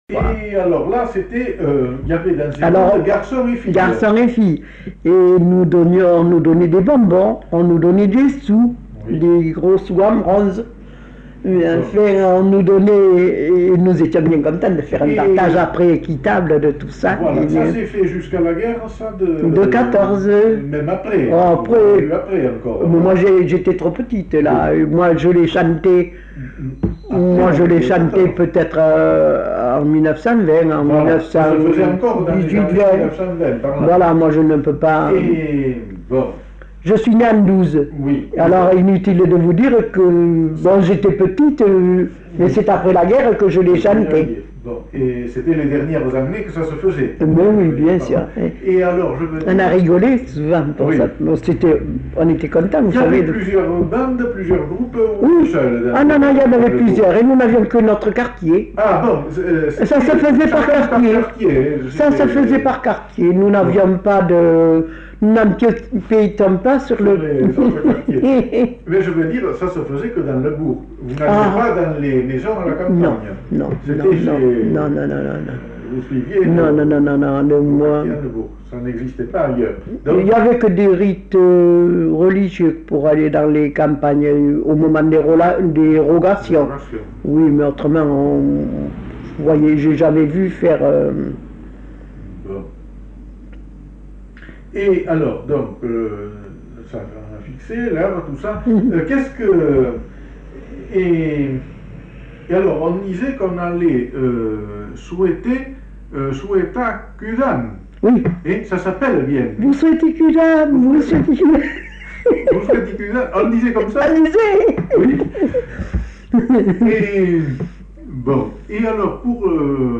Lieu : Grignols
Genre : témoignage thématique